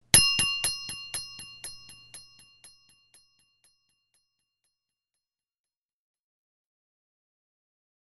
ding_delayed
delay ding metal sound effect free sound royalty free Sound Effects